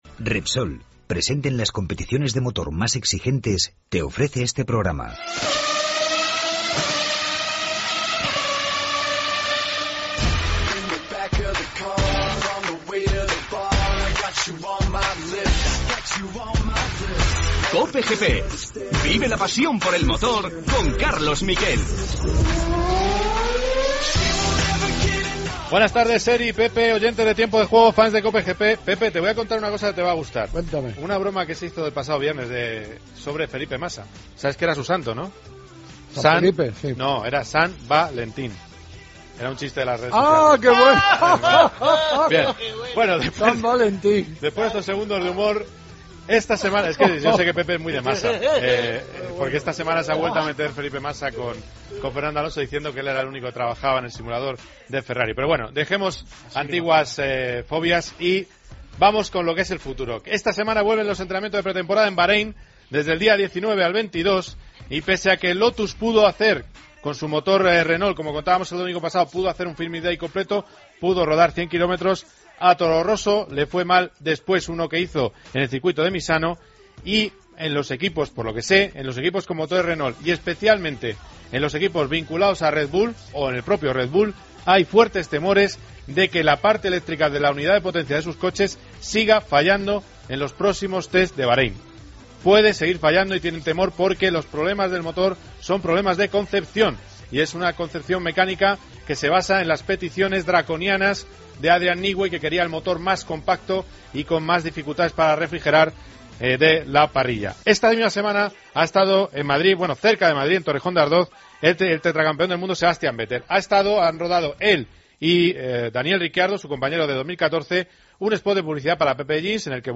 AUDIO: Entrevista a Nico Terol sobre su equipo ciclista y el comienzo de la pretemporada de Moto2.